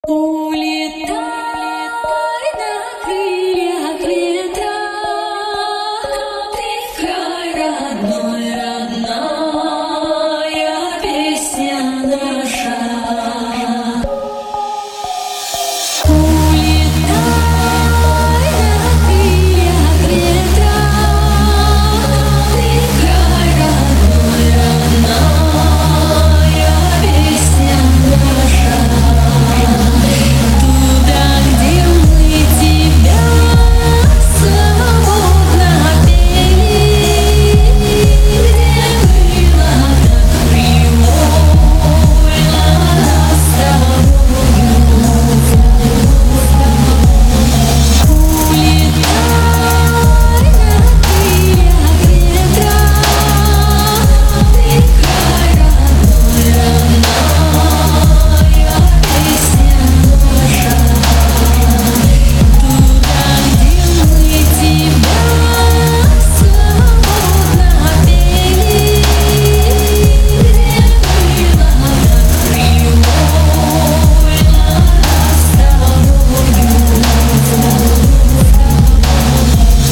атмосферные
Electronic
фолк
Bass
Vocal dubstep
Отличный ремикс на народную песню.